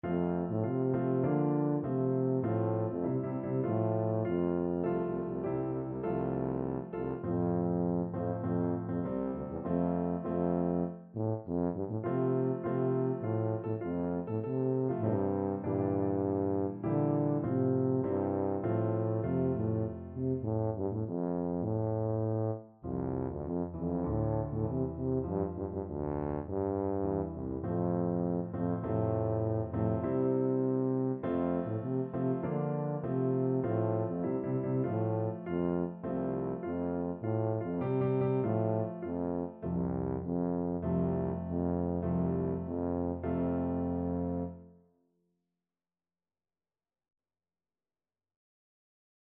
2/4 (View more 2/4 Music)
F major (Sounding Pitch) (View more F major Music for Tuba )
Traditional (View more Traditional Tuba Music)